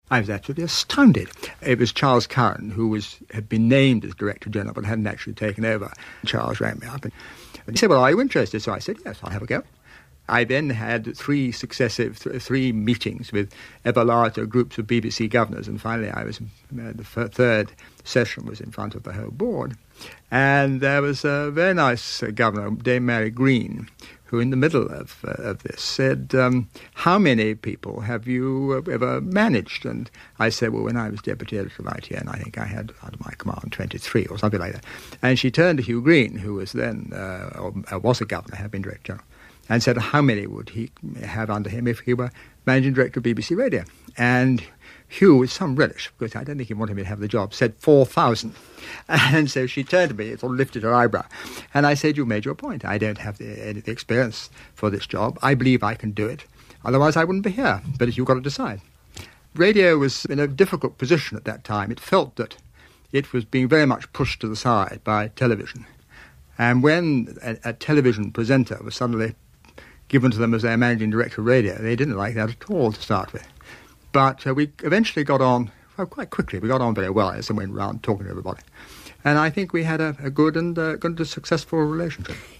Hear here, in the delicious RP for which he was a great advocate, Ian telling of his surprise at being approached to take up the radio role.